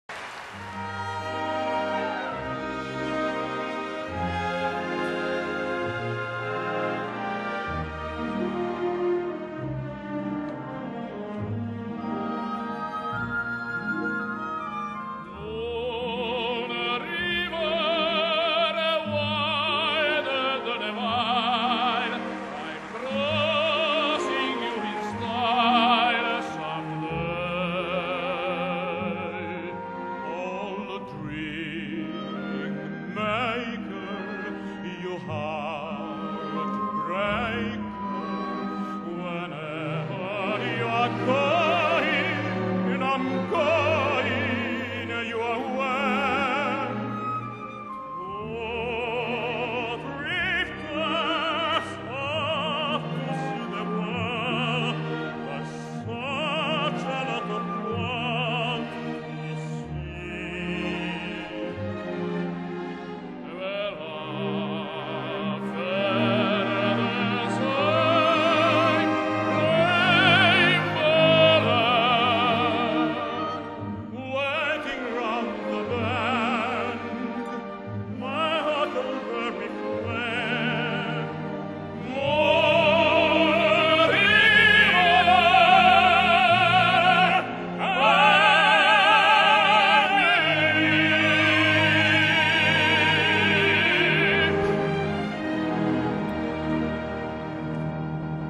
现场观众高达56000人，20亿电视观众收看了这一节目，而音乐会音像制品销售量更是高达1500万张。